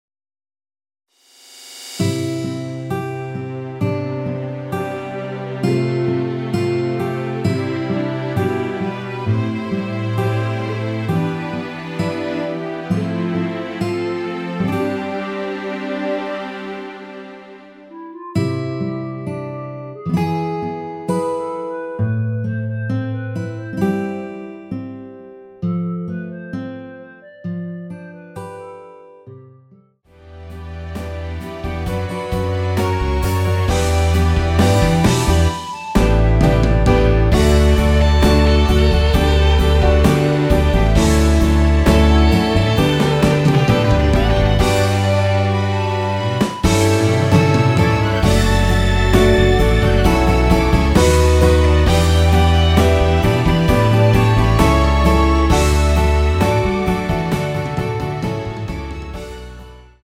원키에서(+4)올린 멜로디 포함된 MR입니다.
멜로디 MR이라고 합니다.
앞부분30초, 뒷부분30초씩 편집해서 올려 드리고 있습니다.
중간에 음이 끈어지고 다시 나오는 이유는